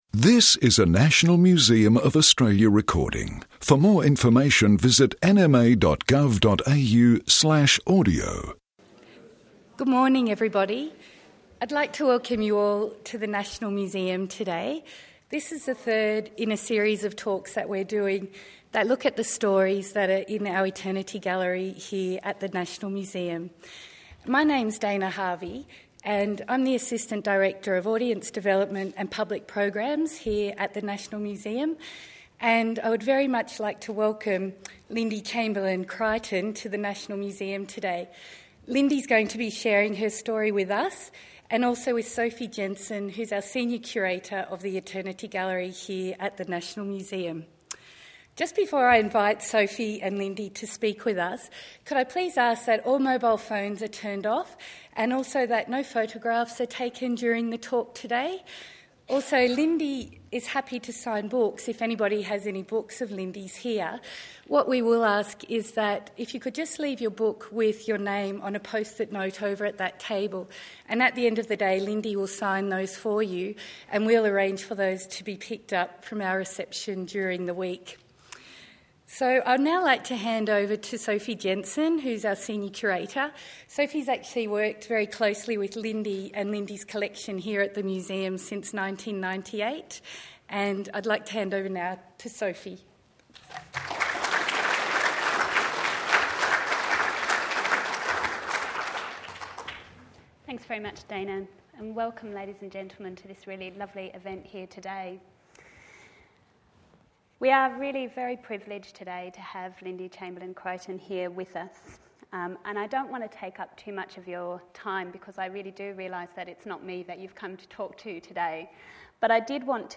Eternity series 14 Oct 2007 Conversation with Lindy Chamberlain-Creighton Lindy Chamberlain-Creighton recounts events since her daughter Azaria was taken from a tent in Australia’s Northern Territory in 1980. She speaks about the National Museum’s Chamberlain collection and the public’s fascination with the case.